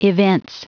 Prononciation du mot evince en anglais (fichier audio)